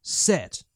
Voices / Male